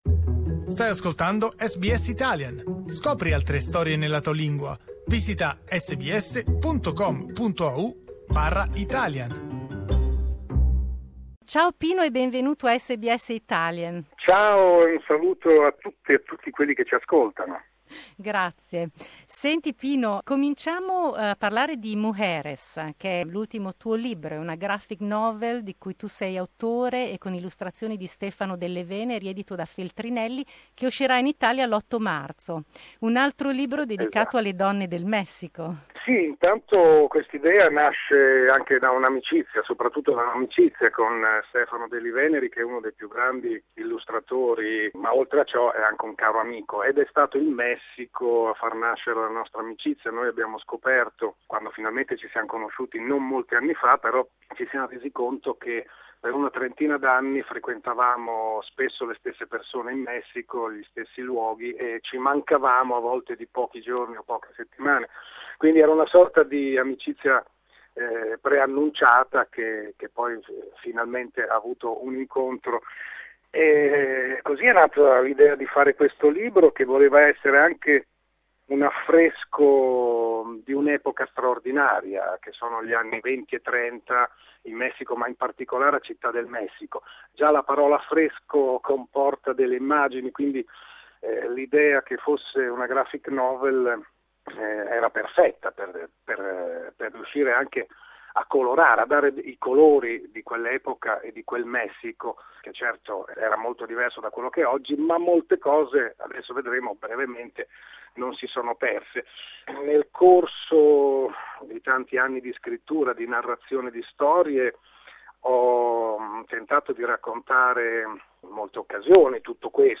Pino Cacucci, scrittore e traduttore, parla del suo ultimo libro "Mujeres" e dei personaggi che lo popolano.